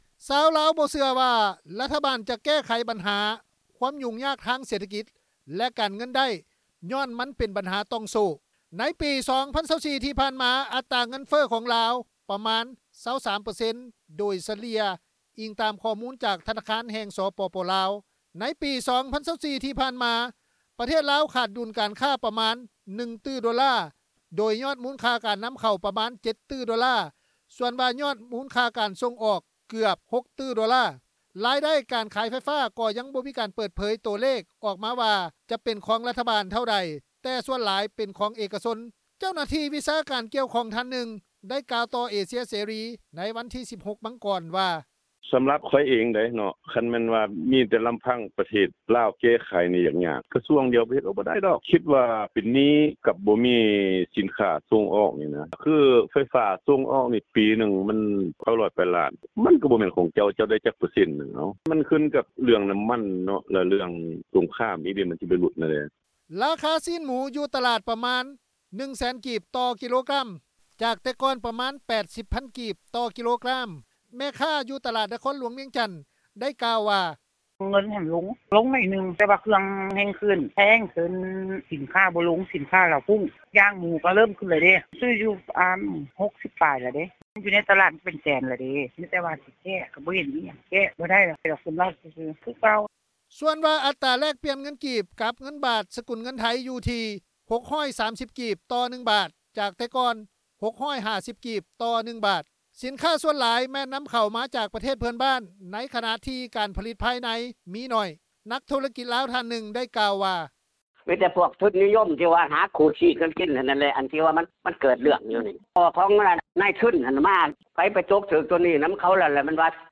ແມ່ຄ້າ ຢູ່ນະຄອນຫຼວງວຽງຈັນ ໄດ້ກ່າວວ່າ:
ນັກທຸລະກິດລາວ ທ່ານໜຶ່ງ ໄດ້ກ່າວວ່າ:
ເຈົ້າໜ້າທີ່ກ່ຽວຂ້ອງ ອີກທ່ານໜຶ່ງ ໄດ້ກ່າວວ່າ: